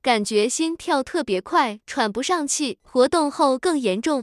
tts_result.wav